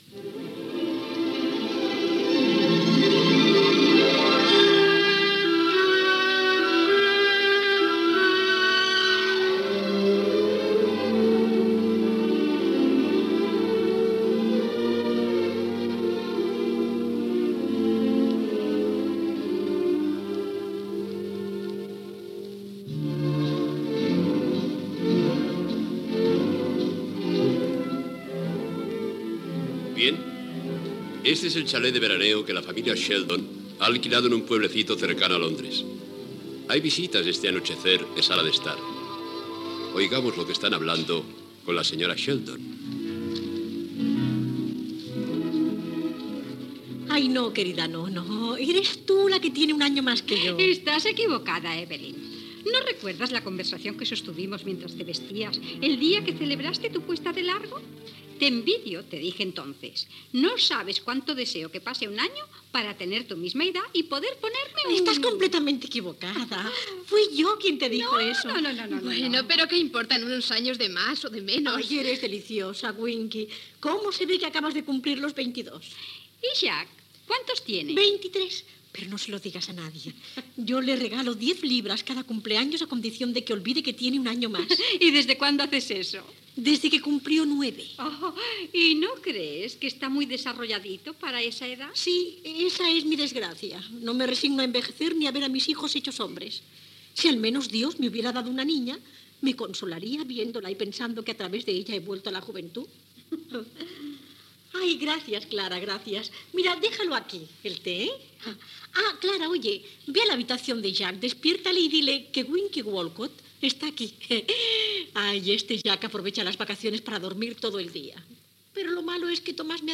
Adaptació radiofònica de l'obra "Aprobado en inocencia", escrita per Luis Peñafiel (Chicho Ibáñez Serrador): presentació i primera escena
Ficció